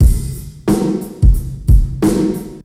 German Hall 91bpm.wav